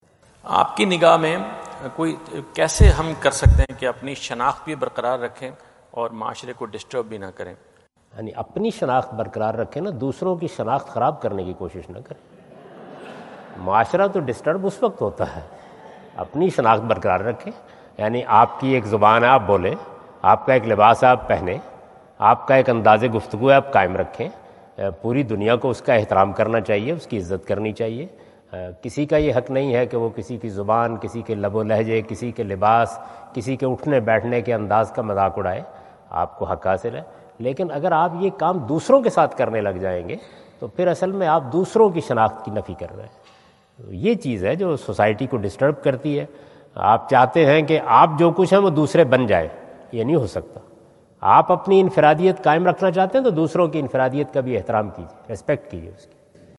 Javed Ahmad Ghamidi answer the question about "How to keep your identity in a society?" During his US visit at Wentz Concert Hall, Chicago on September 23,2017.
جاوید احمد غامدی اپنے دورہ امریکہ2017 کے دوران شکاگو میں "معاشرے میں اپنی شناخت کیسے قائم کریں؟" سے متعلق ایک سوال کا جواب دے رہے ہیں۔